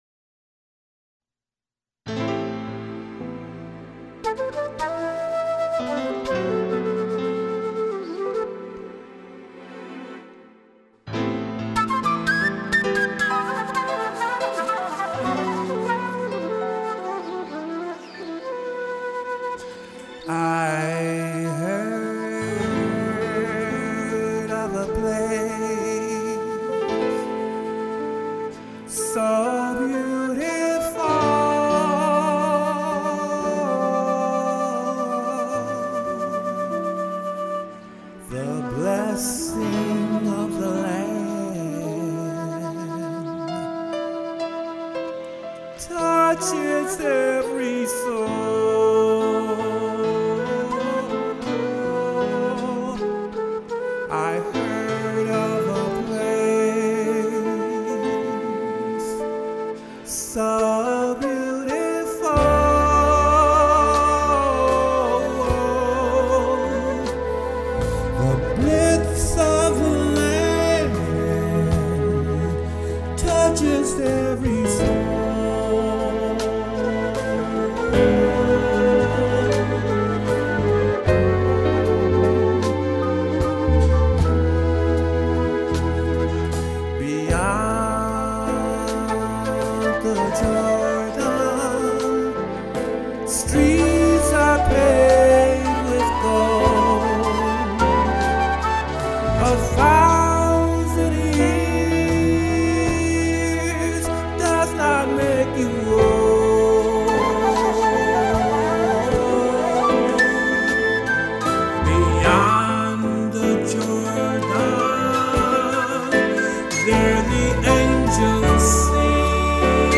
Vocals
Keyboard
Flutes
Jazz/World